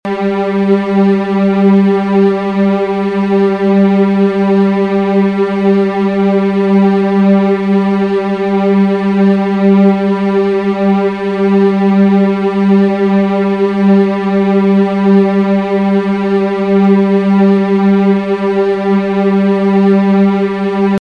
Referenzbeispiele zum Stimmen der E-, A-, D-, G- Geigensaite
Wenn Sie auf die folgenden Links klicken, hören Sie, wie die Saiten klingen und können ihre Geige danach stimmen:
G-Saite (mp3):